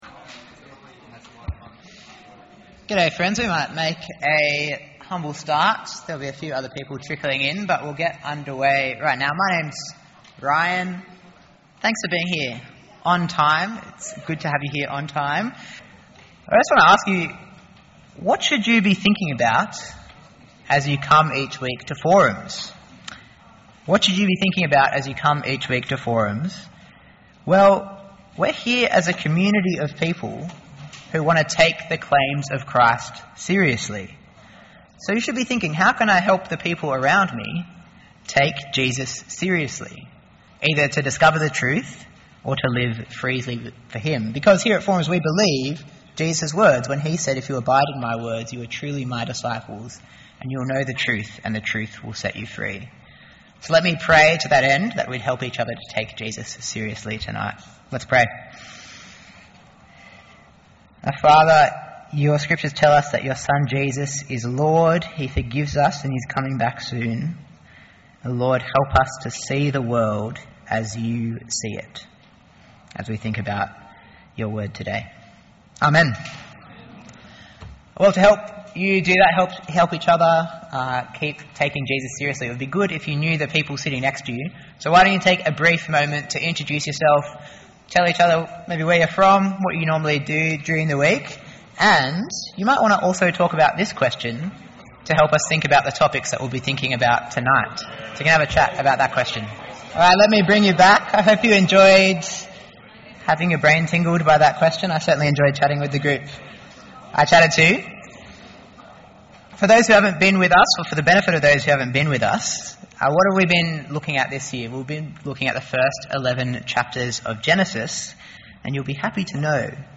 Forum is a 2 hour session of interactive Bible teaching for 18-30 year olds.